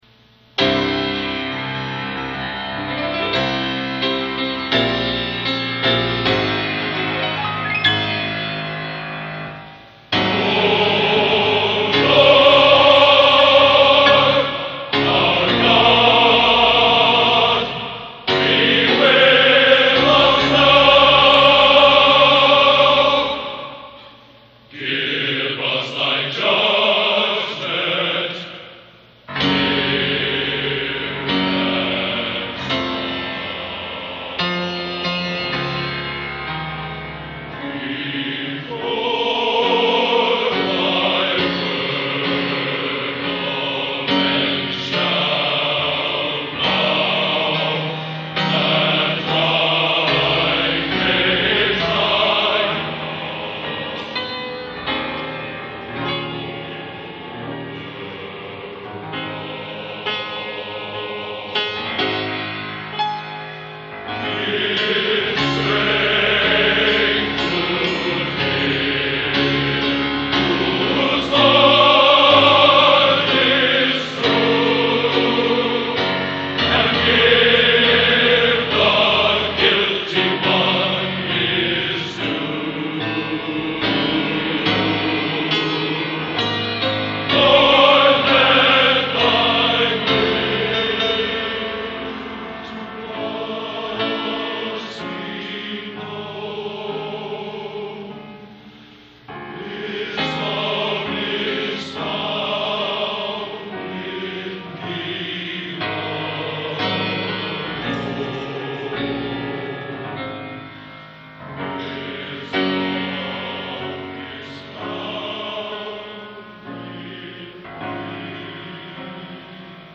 Genre: Opera | Type: